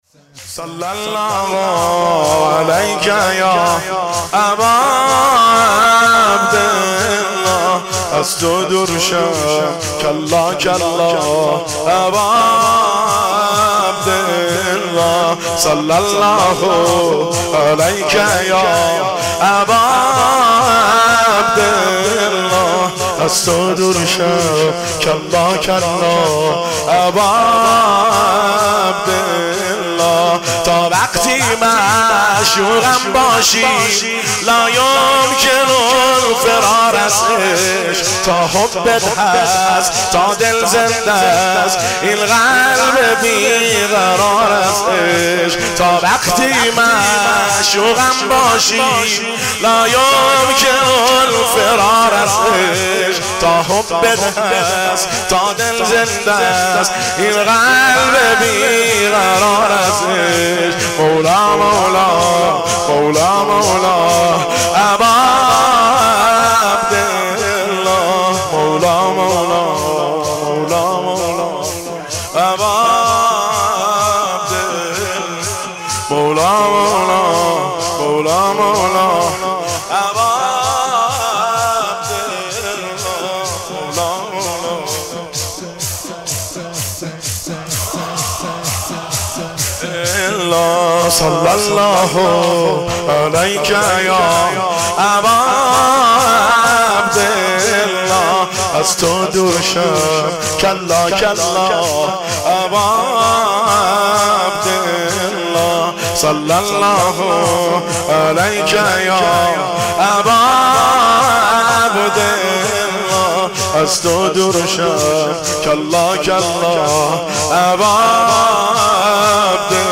دانلود با کیفیت LIVE